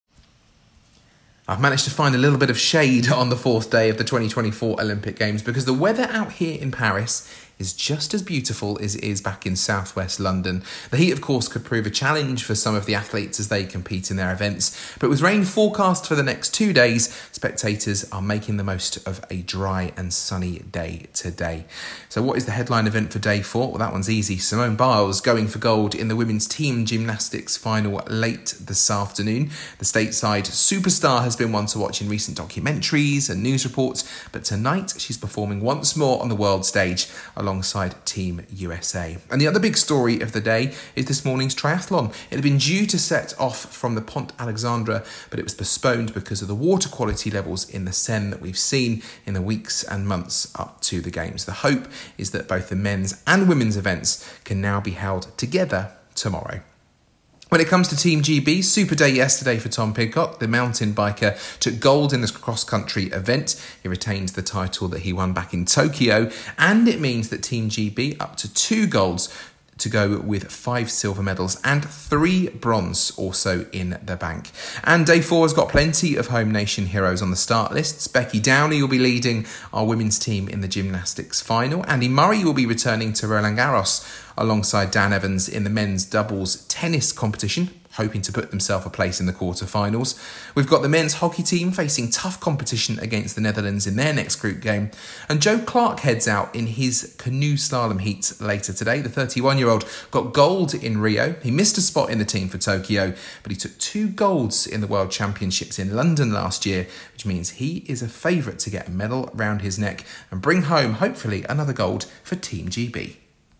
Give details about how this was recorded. reports from day 4 of the Paris Olympics.